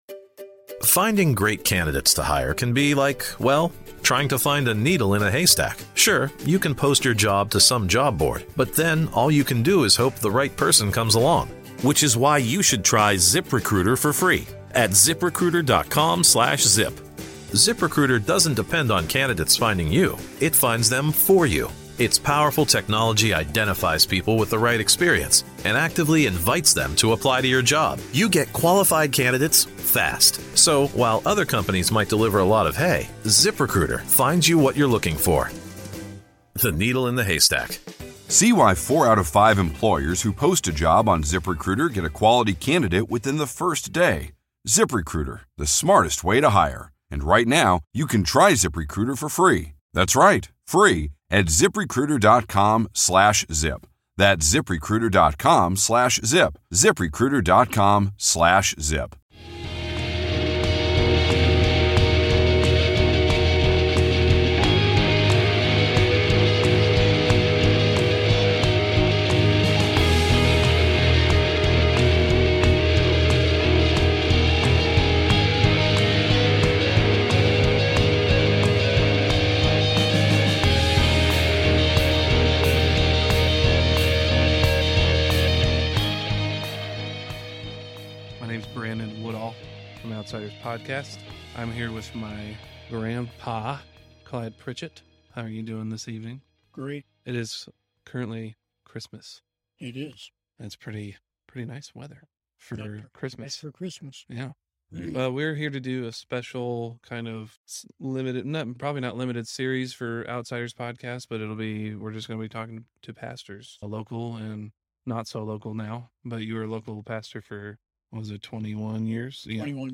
Pastor Interview